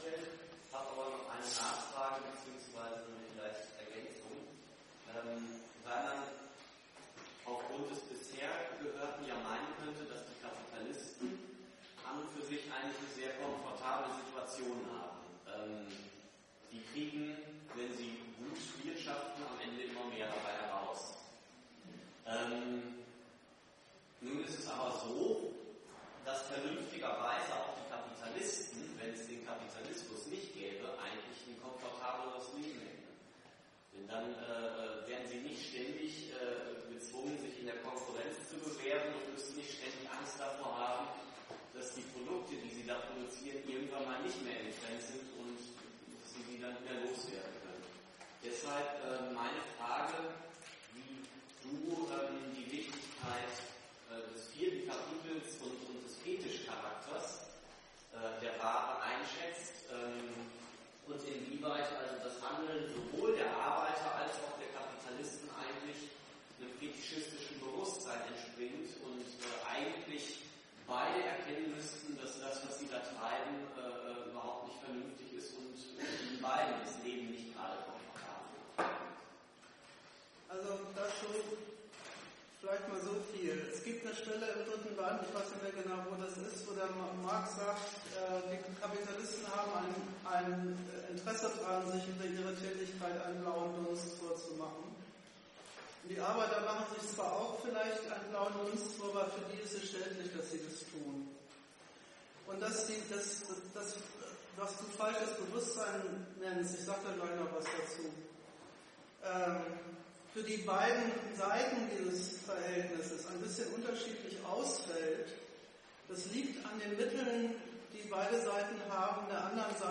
Die Aufzeichnung eines Vortrages in Frankfurt, ergänzt um eine Diskussion anlässlich eines Vortrages zum Thema in Münster.